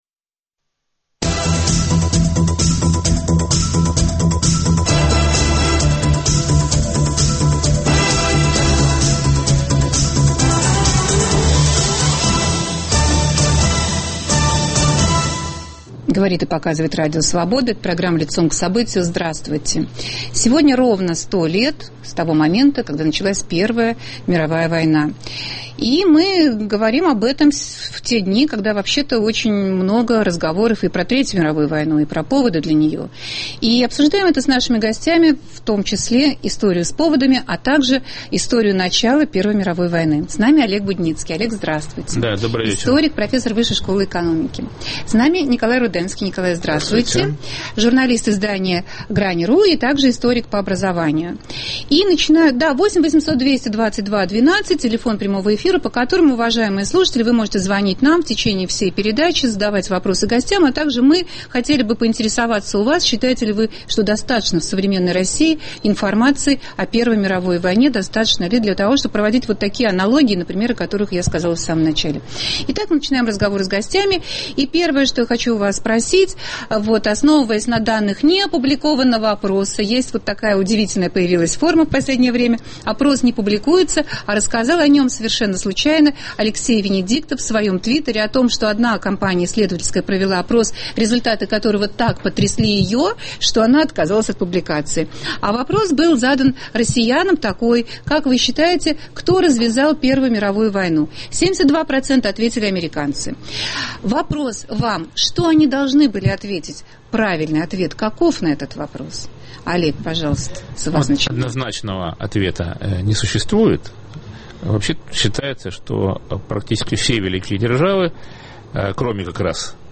Ряд историков проводит параллели между выстрелом в Сараево как поводом к Первой мировой войне и катастрофой малайзиского "Боинга" как возможным поводом для третьей. Корректны ли такие параллели? В столетнюю годовщину Первой мировой в студии программы